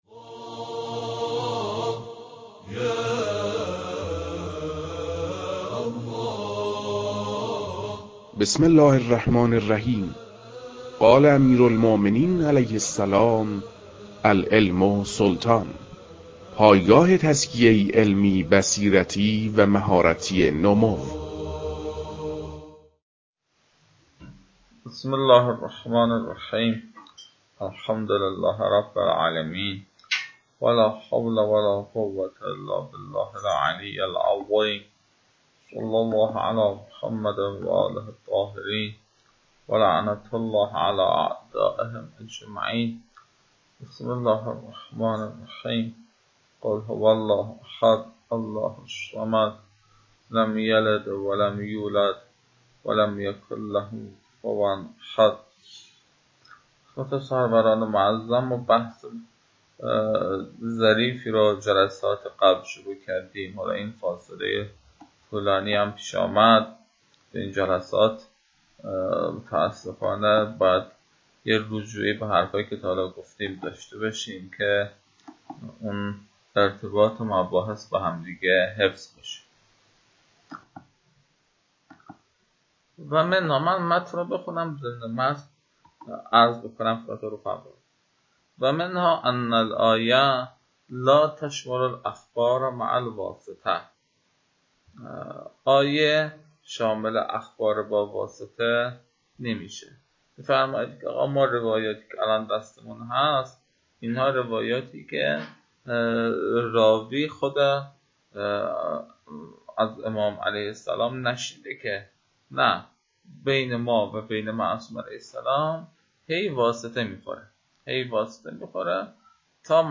محتوای فایل، متن‌خوانی اشکال پایانی فرائد الأصول، ج1، ص: 265 می‌باشد.